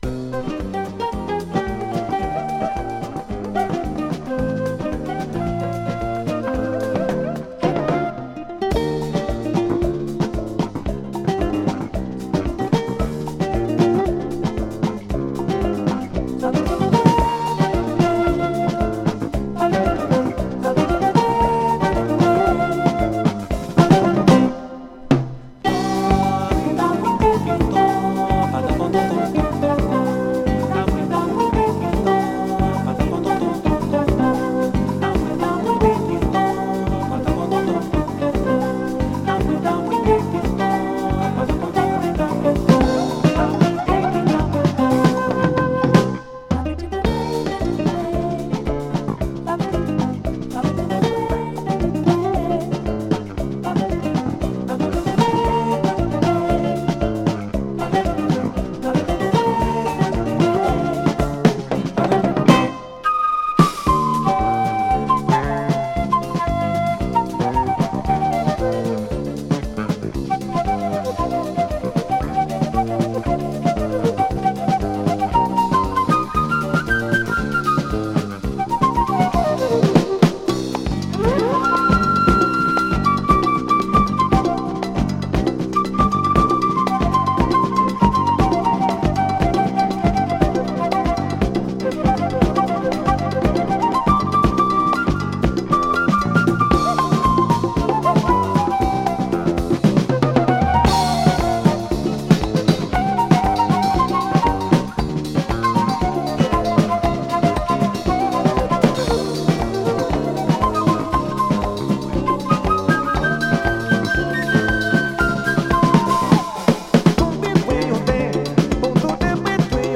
Format : LP
アップ・リフティングのブラジリアン・スキャット・フュージョン